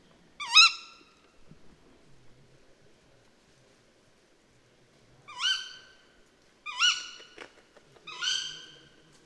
Waldkauz Ruf Weibchen
Der Waldkauz Ruf Weibchen ist deutlich heller und kürzer als der des Männchens. Statt eines tiefen „Hu-huuh“ erklingt ein scharfes „ki-wick“ oder „kjuik“, das meist in Serien oder Einzeltönen geäußert wird. Diese Laute dienen nicht zur Reviermarkierung, sondern sind vor allem zur Kontaktaufnahme gedacht.
Waldkauz-Ruf-Weibchen-Voegel-in-Europa.wav